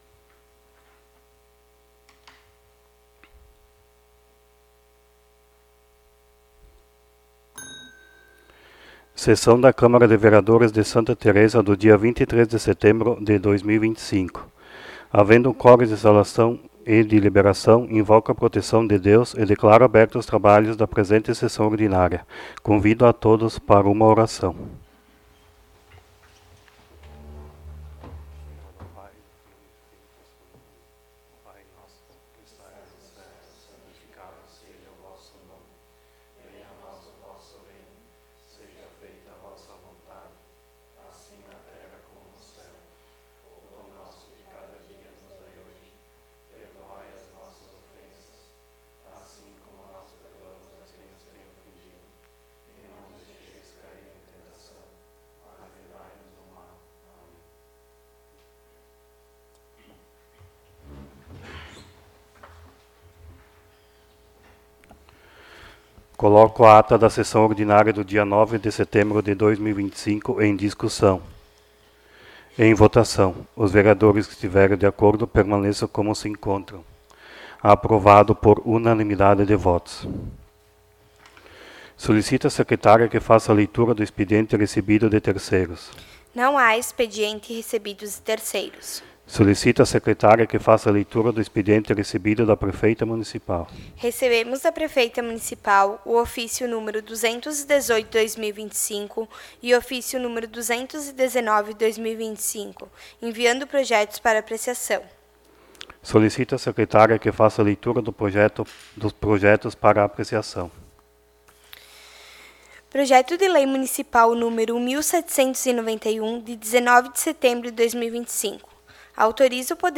16° Sessão Ordinária de 2025